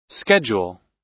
Transcription and pronunciation of the word "schedule" in British and American variants.
schedule__us_1.mp3